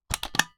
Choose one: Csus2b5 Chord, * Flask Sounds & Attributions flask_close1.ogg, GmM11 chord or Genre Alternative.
* Flask Sounds & Attributions flask_close1.ogg